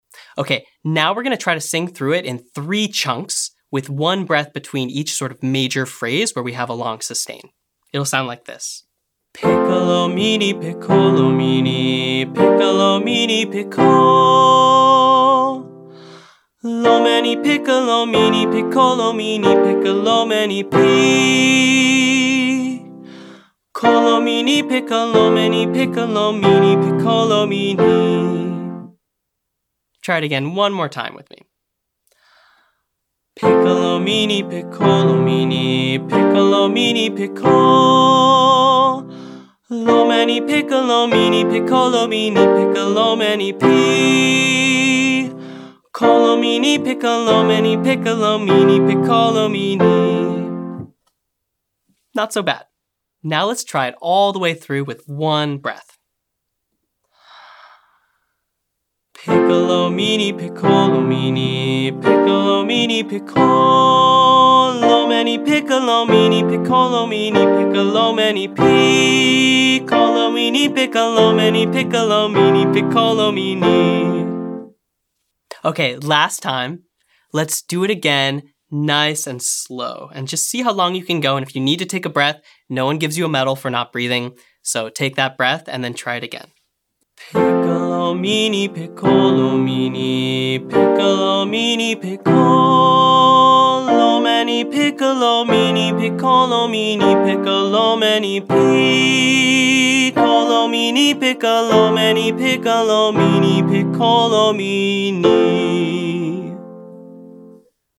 Singing Longer Phrases - Online Singing Lesson